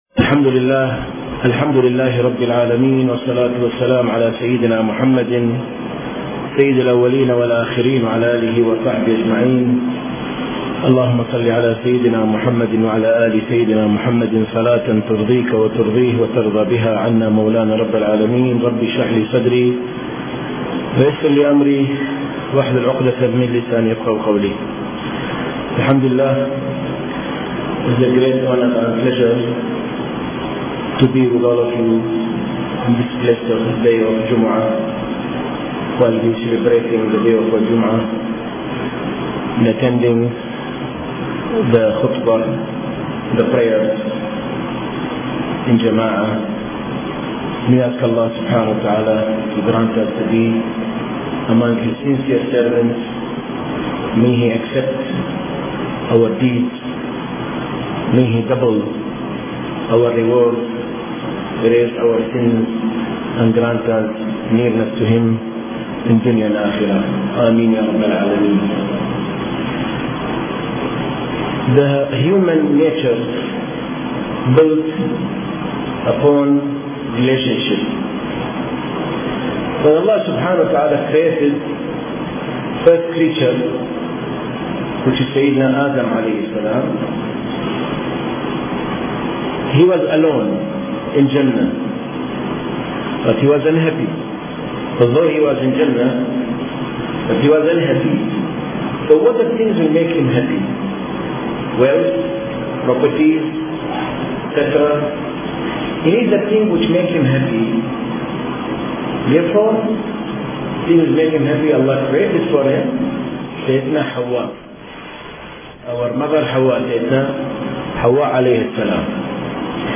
Unity & The Islamic Brotherhood | Audio Bayans | All Ceylon Muslim Youth Community | Addalaichenai
Kollupitty Jumua Masjith